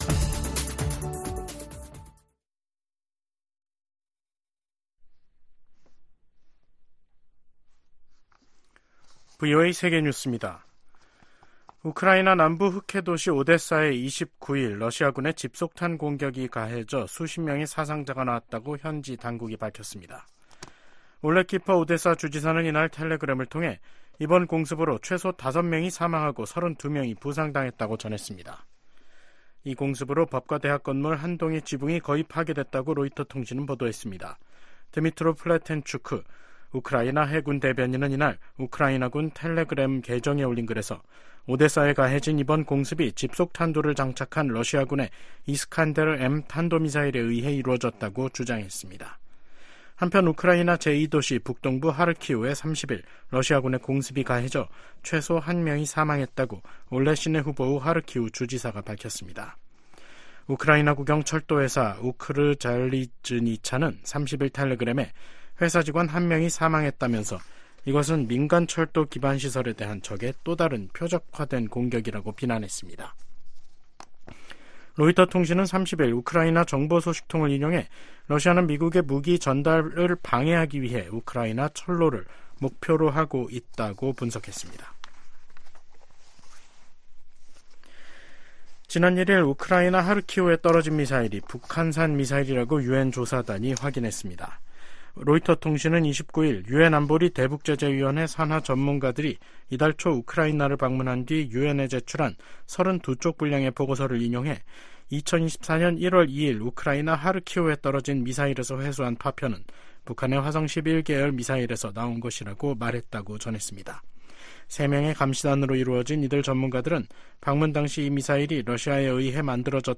VOA 한국어 간판 뉴스 프로그램 '뉴스 투데이', 2024년 4월 30일 3부 방송입니다.